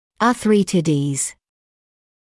[ɑː’θrɪtədiːz][аː’сритэдиːз]артриты (форма мн.числа от arthritis)